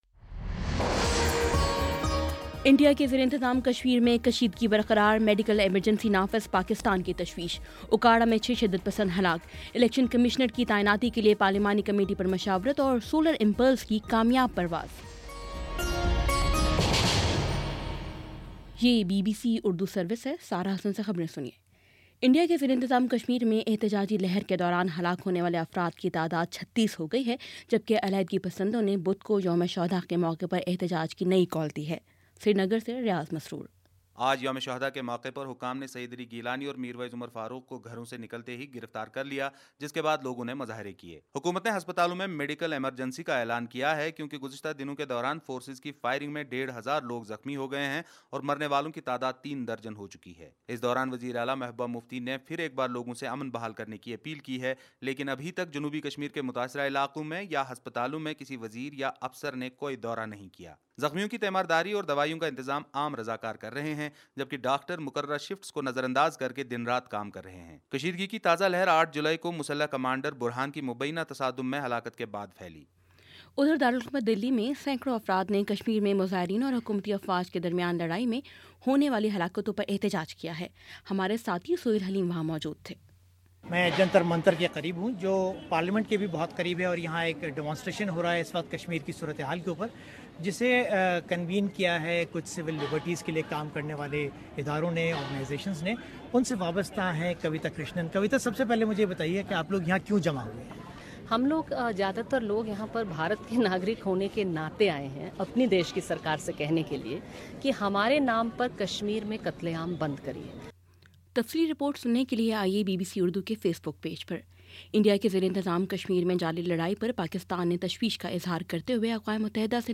جولائی 13 : شام پانچ بجے کا نیوز بُلیٹن